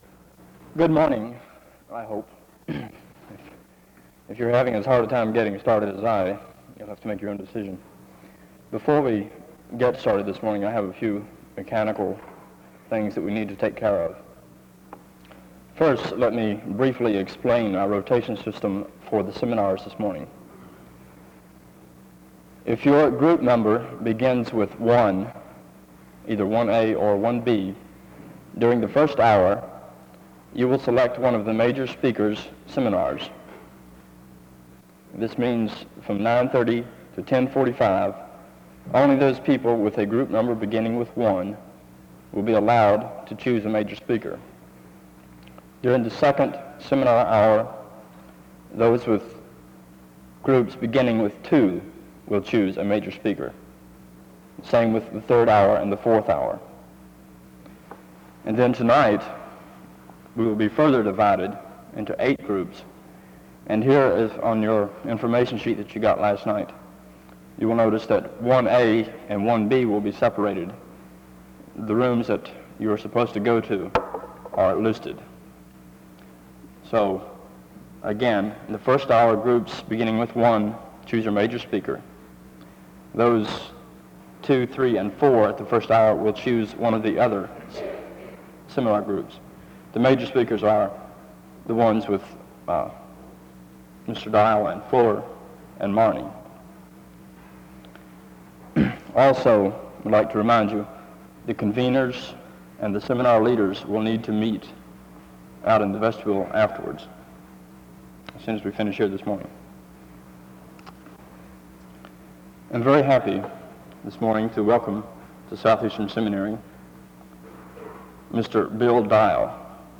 SEBTS Missions Conference